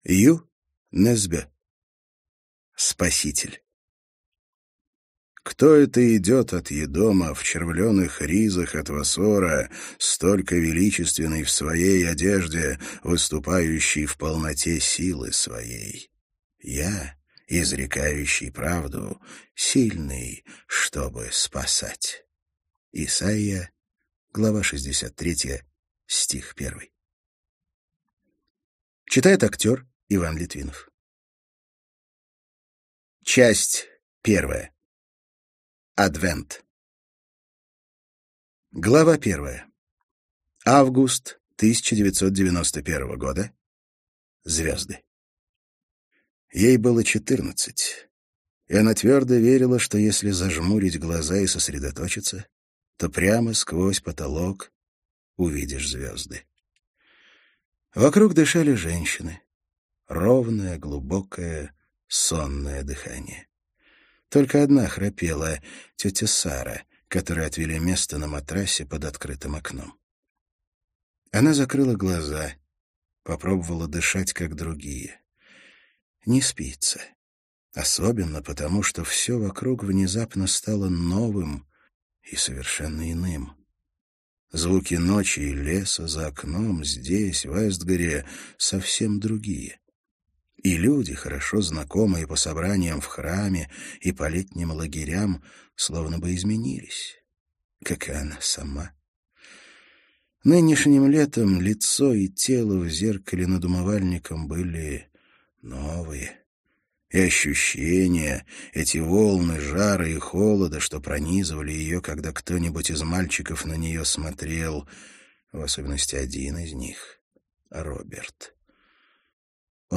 Аудиокнига Спаситель - купить, скачать и слушать онлайн | КнигоПоиск